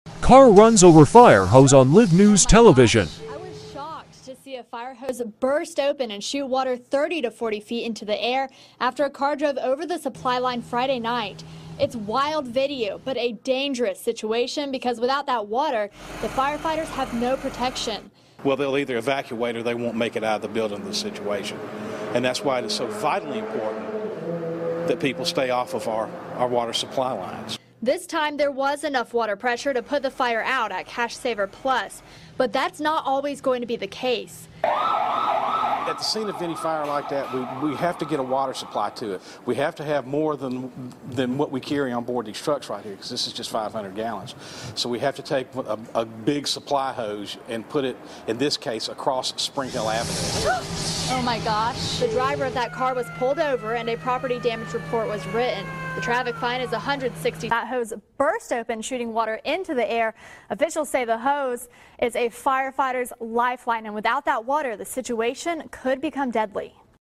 Car runs over fire hose sound effects free download
Car runs over fire hose in Live TV